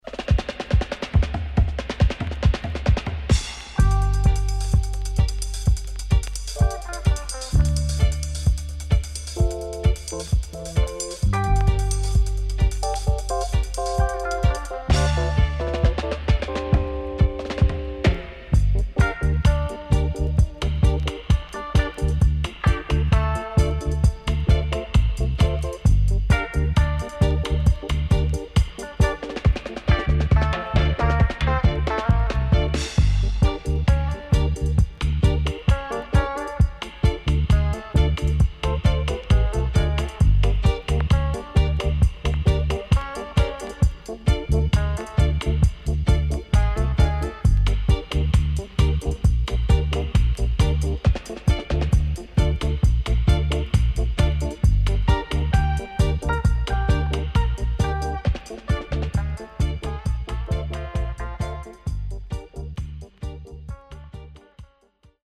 SIDE A:盤質は良好です。